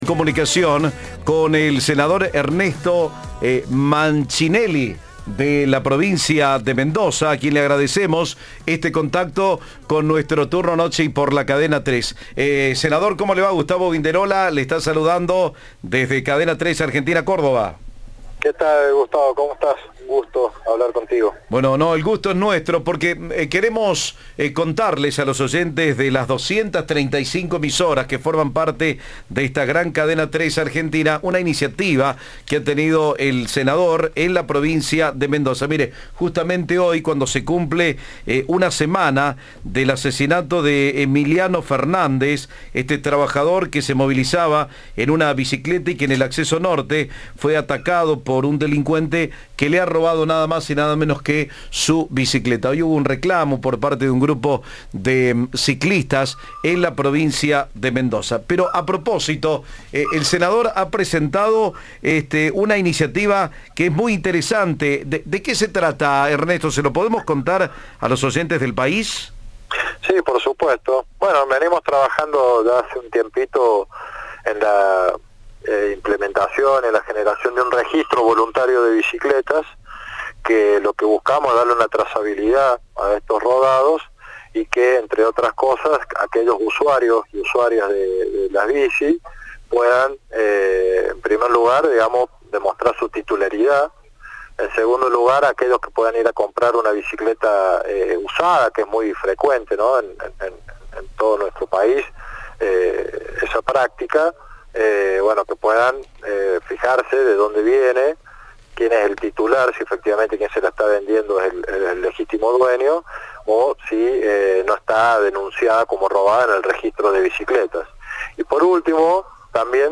El proyecto fue aprobado por los senadores provinciales y resta ser tratado por diputados. Uno de los autores del proyecto, Ernesto Mancinelli, dio detalles a Cadena 3.